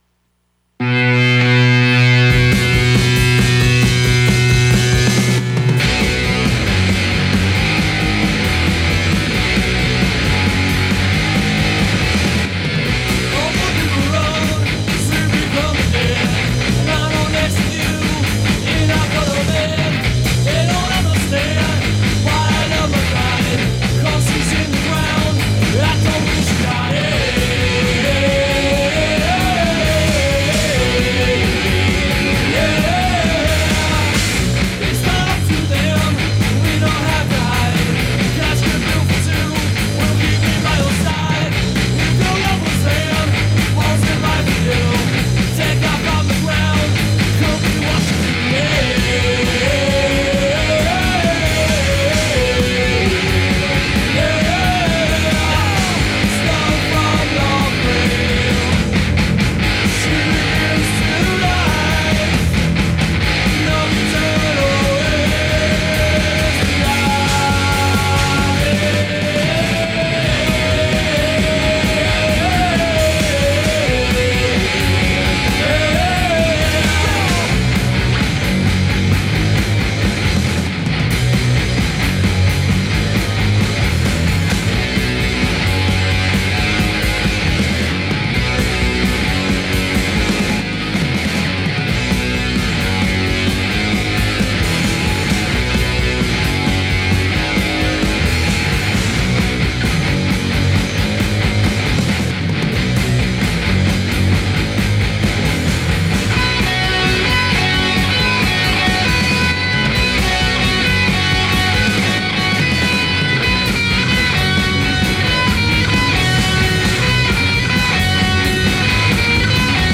Punk & assorted nasties.